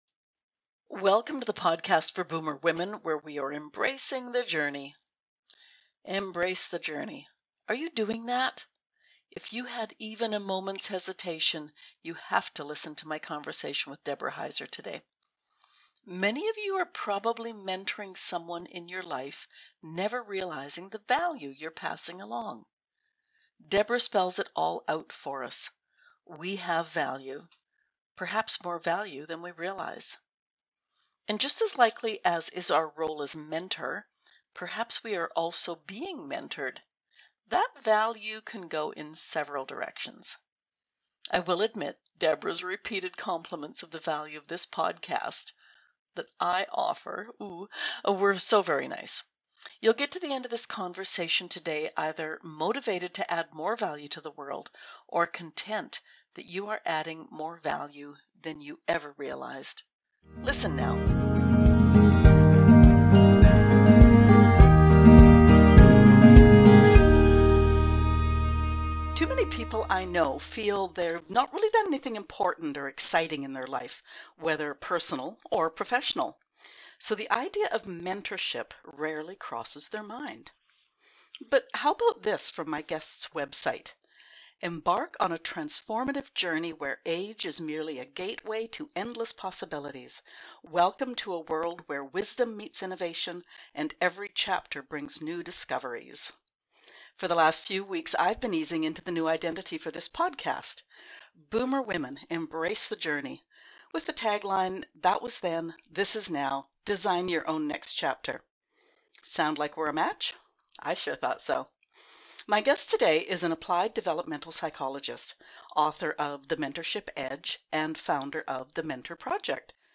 Our conversation today is about mentorship.